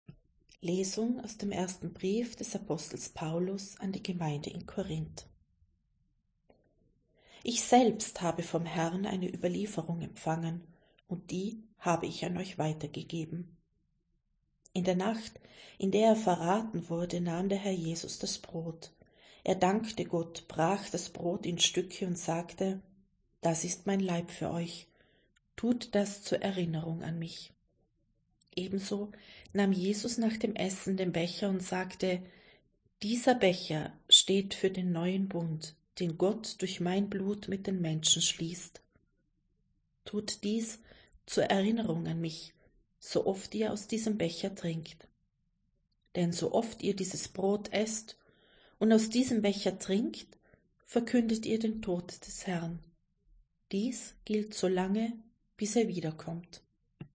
Wenn Sie den Text der 2. Lesung aus dem ersten Brief des Apostels Paulus an die Gemeinde in Korínth anhören möchten: